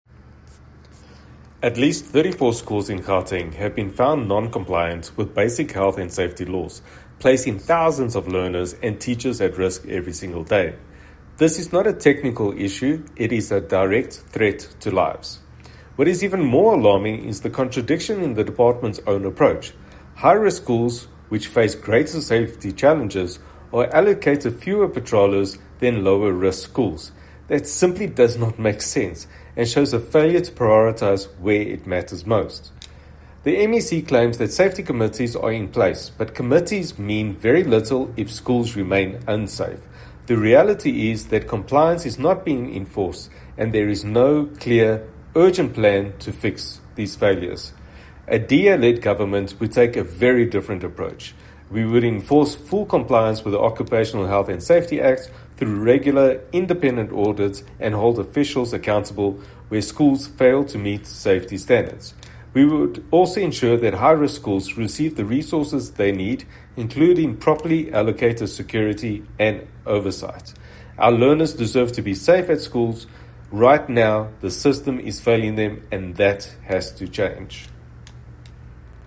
Note to Editors: Please find a soundbite in English from DA MPL, Sergio Isa Dos Santos